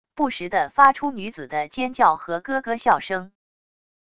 不时的发出女子的尖叫和咯咯笑声wav音频